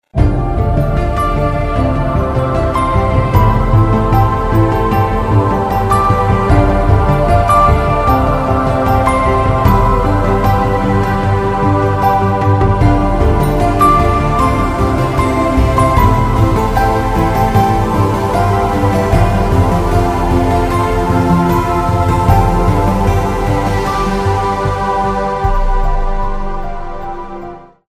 رینگتون پرتحرک و زیبای
(برداشتی آزاد از موسیقی های بی کلام خارجی)